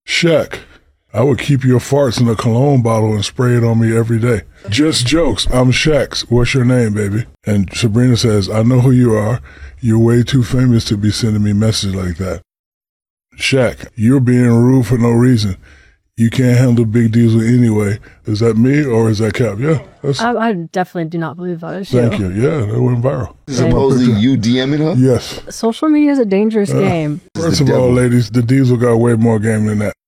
According to the Diesel the messages sent from an account bearing his likeness did not come from him. Shaq was on his podcast reading the fake messages and even joked, that his rizz is a bit smoother than some of the wild and out of pocket messages that were being sent to the “When Did You Get Hot” singer.